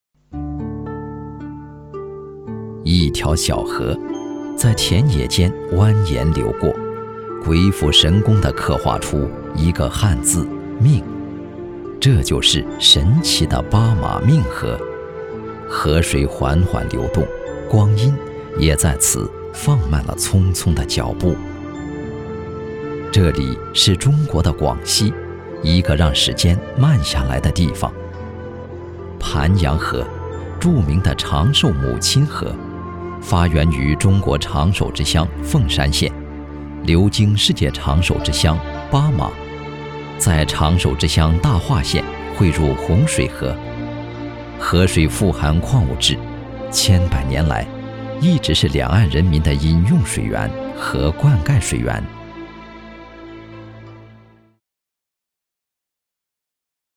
国语中年大气浑厚磁性 、沉稳 、娓娓道来 、男专题片 、宣传片 、80元/分钟男S390 国语 男声 宣传片-企业汇报-企业专题-大气浑厚 大气浑厚磁性|沉稳|娓娓道来